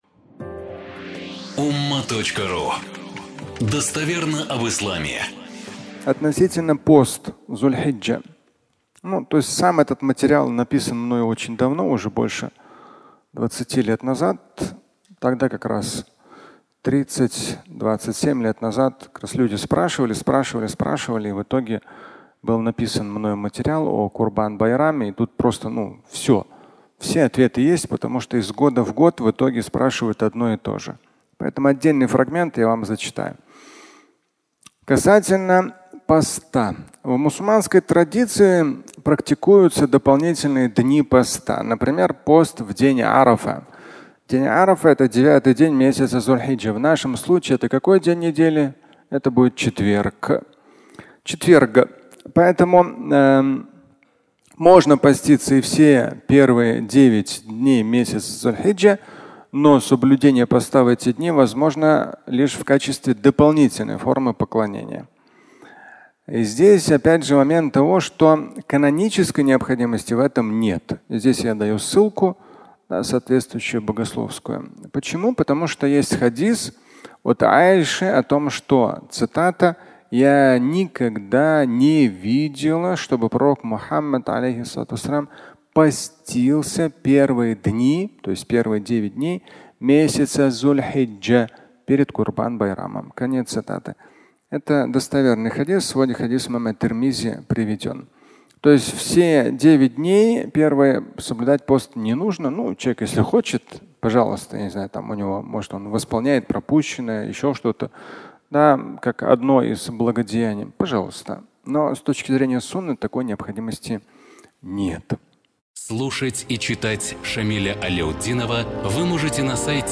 Пост в Зуль-хиджа (аудиолекция)
Пятничная проповедь